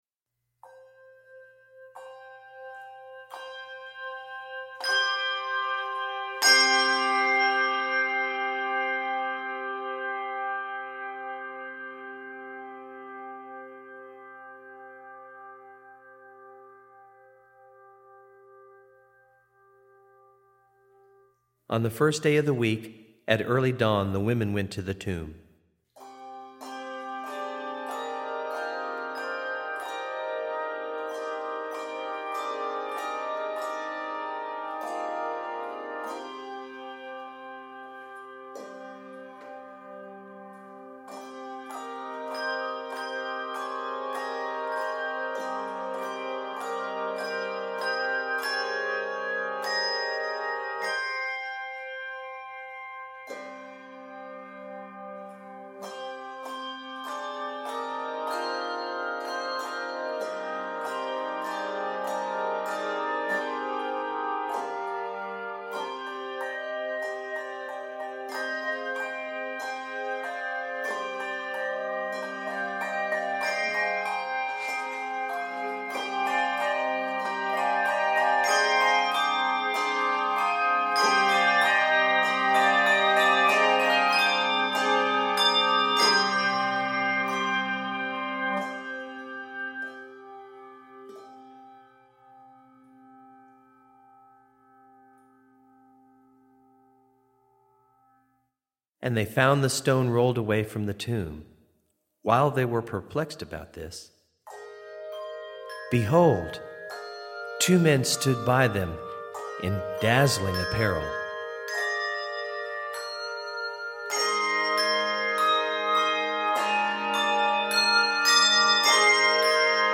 Easter piece
for 3-5 octave handbell choir with optional narration.